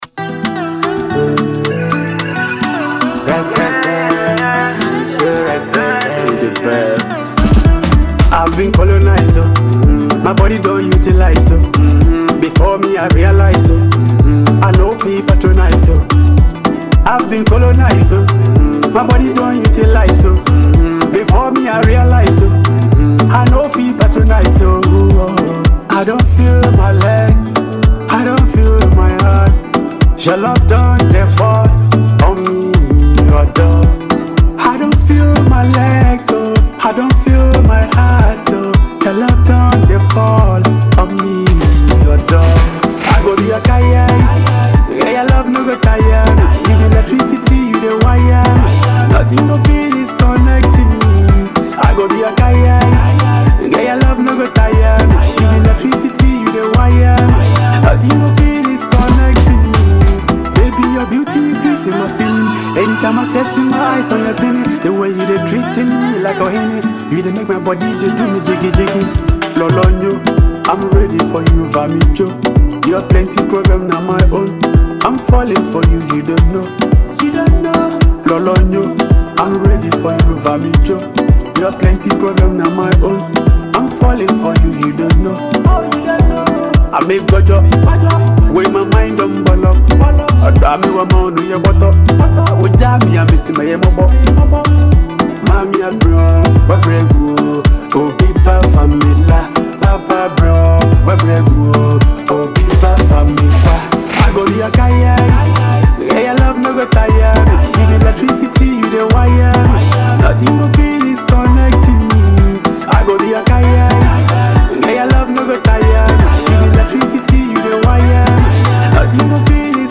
a fusion of Afrobeat, highlife, and contemporary sounds